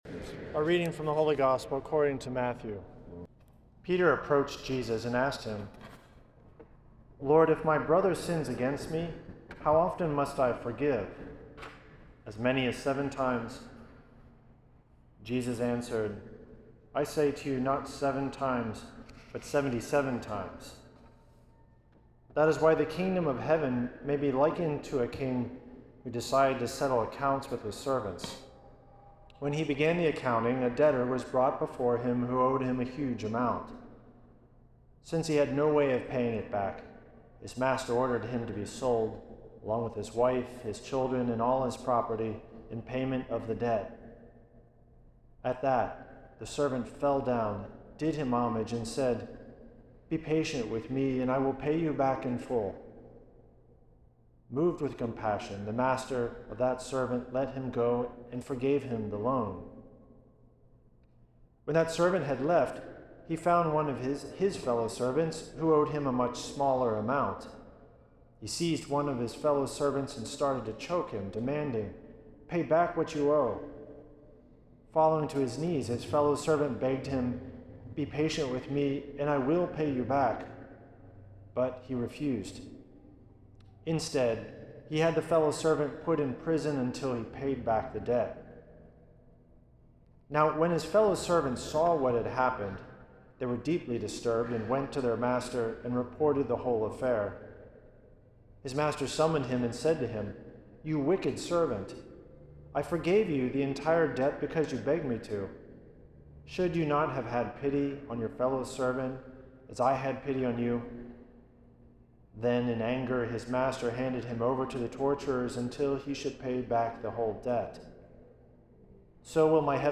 Homily
for the 11th Sunday in Ordinary Time at St. Patrick's Old Cathedral in NYC.